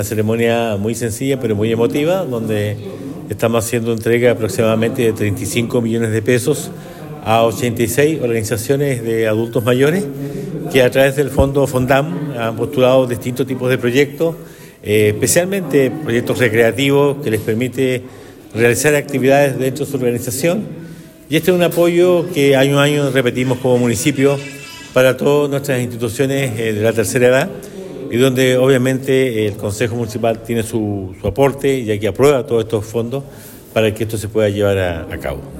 El Alcalde Emeterio Carrillo indicó que esto permitirá a las agrupaciones de adultos mayores poder llevar a cabo sus distintos proyectos y actividades.